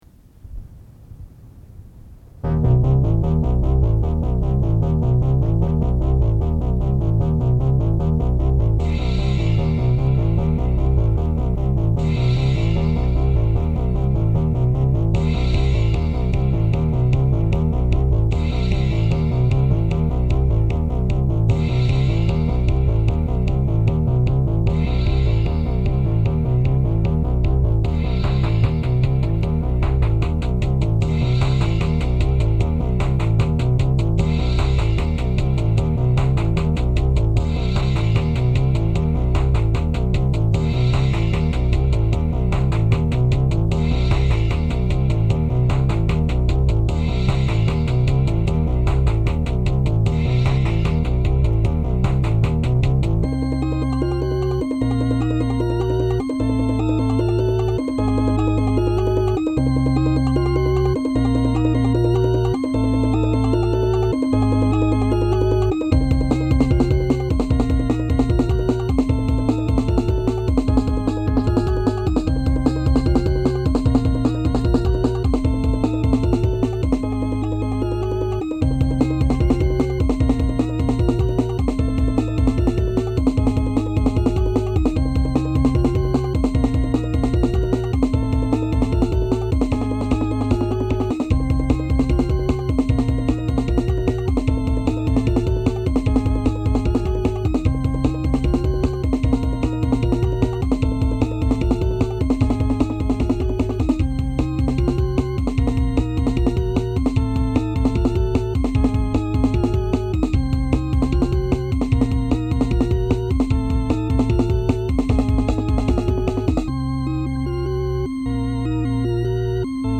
Tags: nightmares dj mixes rock n roll